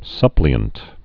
(sŭplē-ənt)